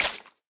Minecraft Version Minecraft Version snapshot Latest Release | Latest Snapshot snapshot / assets / minecraft / sounds / block / sponge / wet_sponge / step2.ogg Compare With Compare With Latest Release | Latest Snapshot